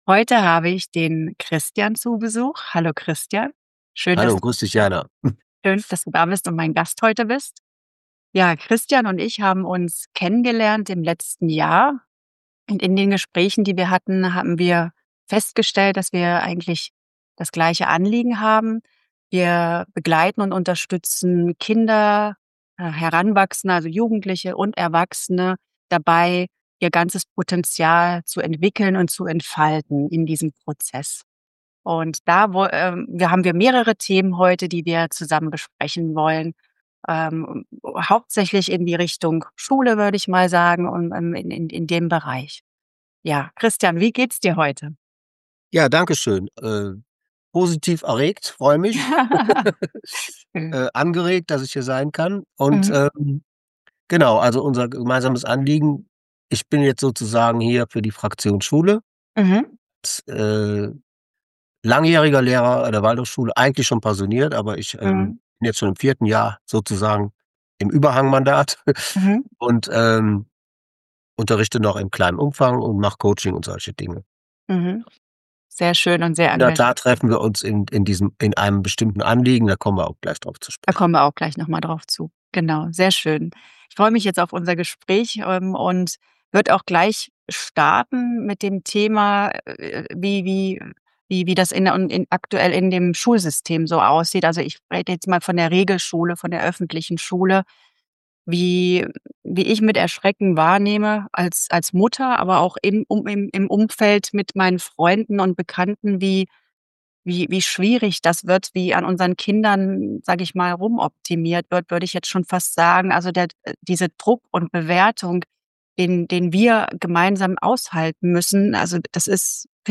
Ein Gespräch, das Mut macht und zeigt: Kleine Schritte wirken, wenn wir sie regelmässig gehen.